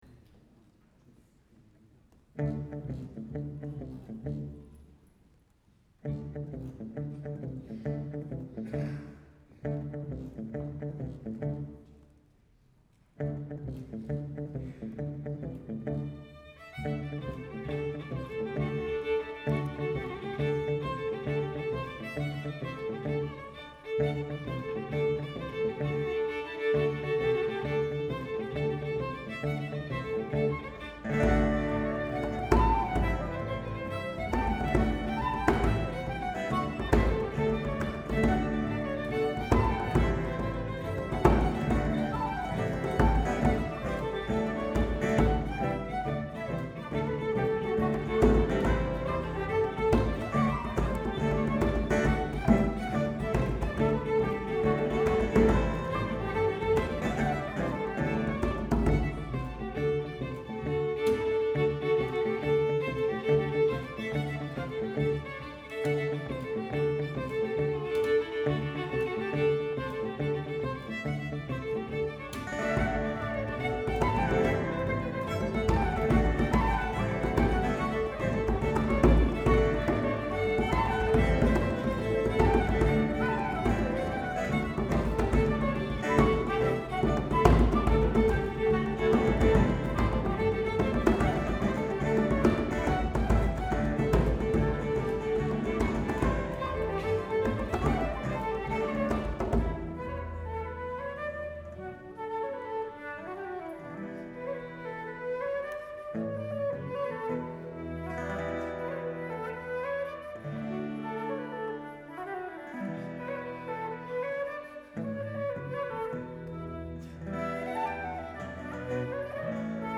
Santa Barbara City College Choir Concert, Spring 2008
Pre-Concert